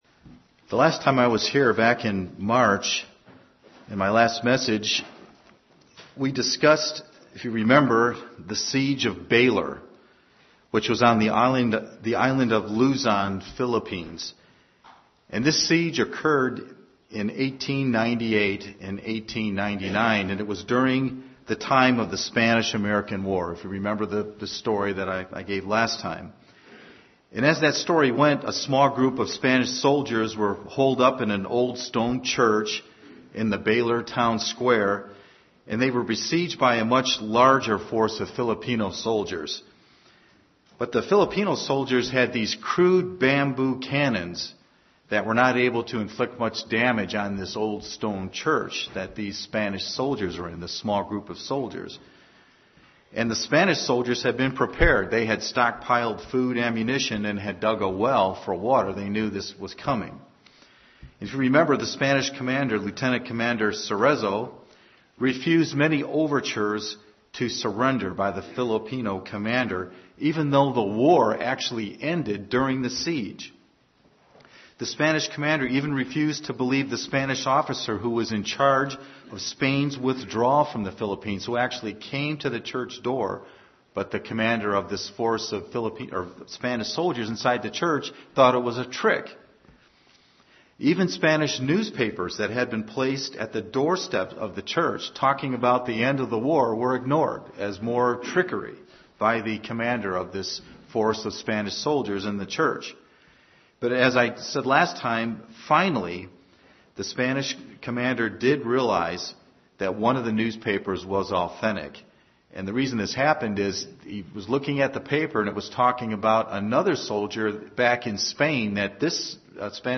Sermons
Given in Ft. Wayne, IN